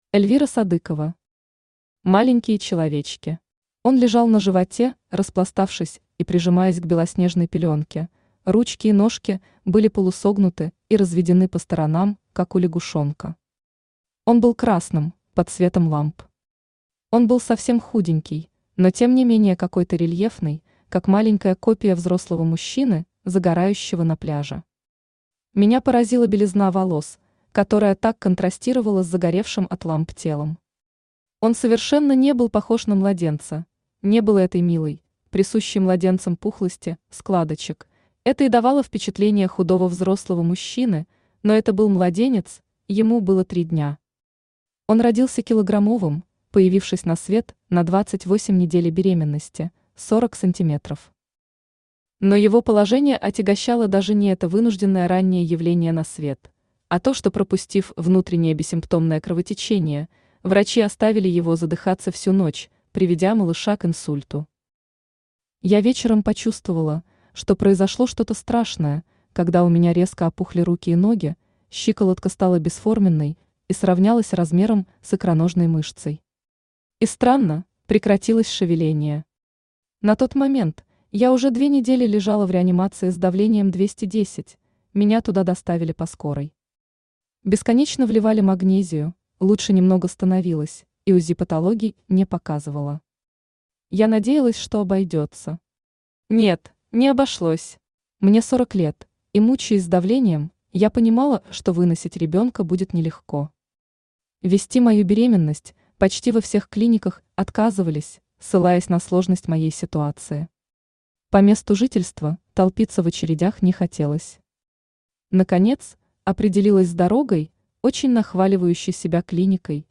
Аудиокнига Маленькие человечки | Библиотека аудиокниг
Aудиокнига Маленькие человечки Автор Эльвира Альфредовна Садыкова Читает аудиокнигу Авточтец ЛитРес.